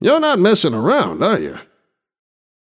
Shopkeeper voice line - You’re not messin‘ around, are ya?